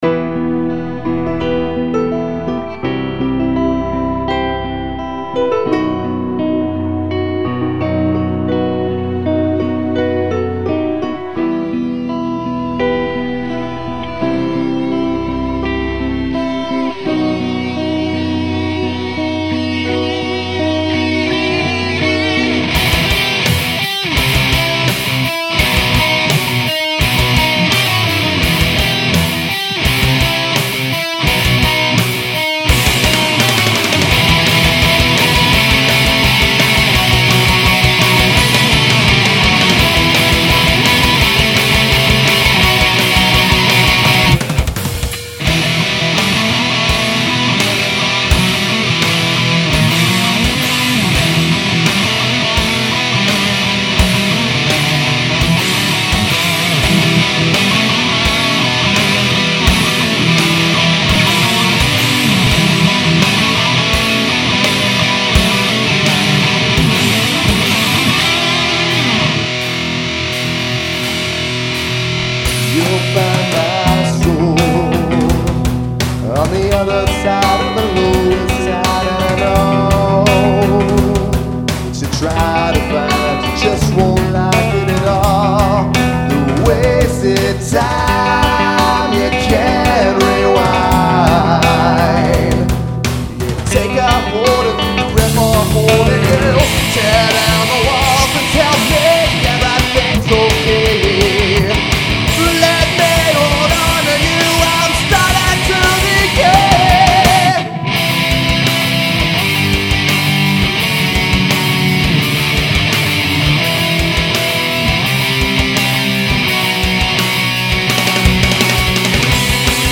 I'm by NO means a professional producer, i have a small home studio, and i'm recording all guitars with a single SM57.
I know i need to do some research on EQing and frequencies, because alot of the song doesn't blend like i want it to.
Keep in mind, all the solos in this song are just improv, they are placeholders until i actually write some. the songs attached to this thread.